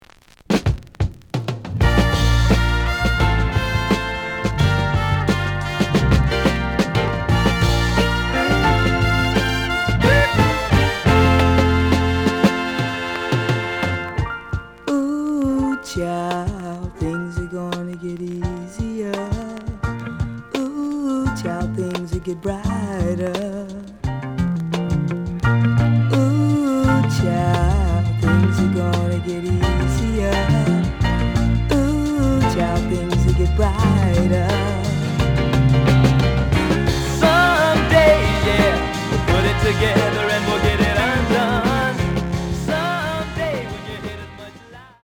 The audio sample is recorded from the actual item.
●Genre: Soul, 70's Soul
Slight damage on both side labels. Plays good.)